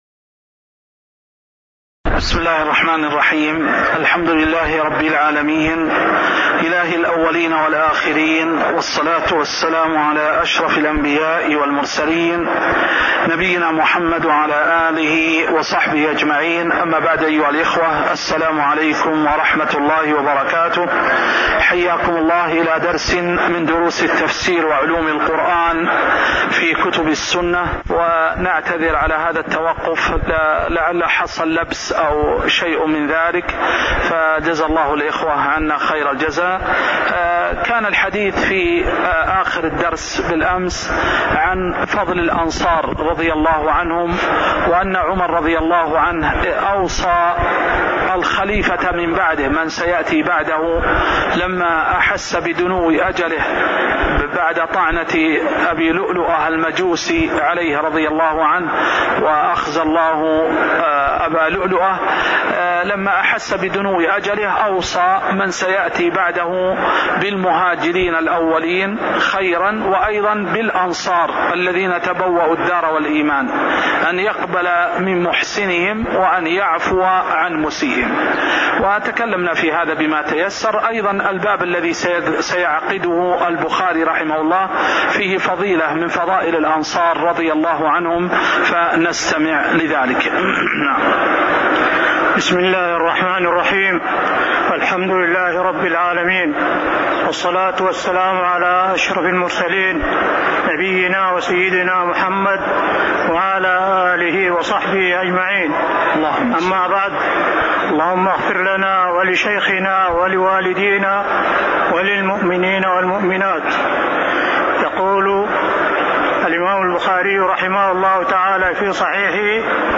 تاريخ النشر ١٢ صفر ١٤٤٠ هـ المكان: المسجد النبوي الشيخ